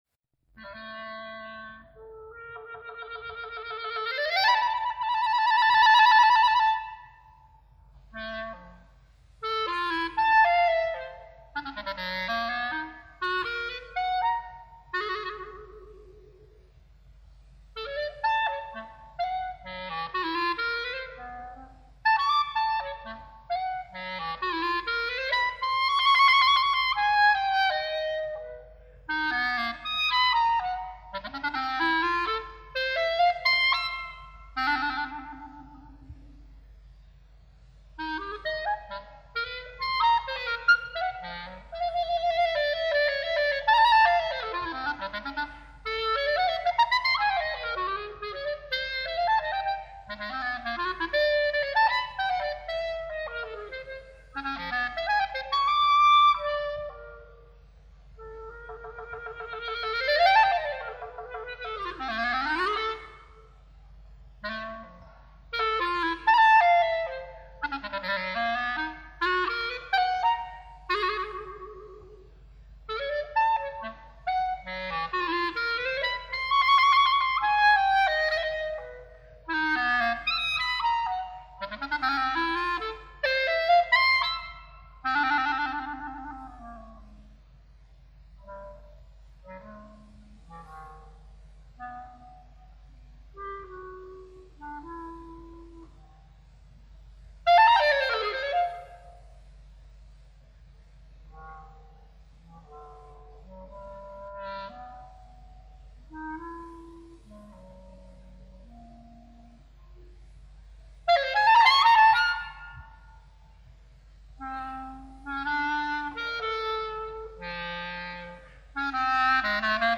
per clarinetto (solo)
Giocoso ritmico  (2'32" - 2,4MB)